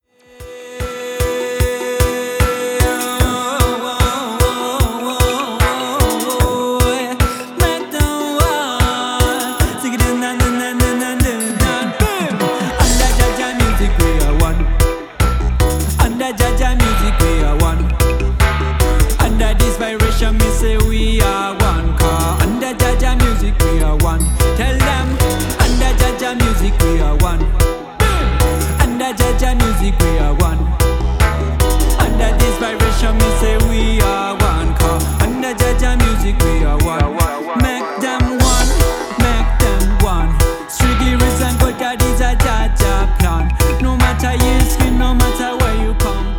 the Maresmian Reggae MC ambassor.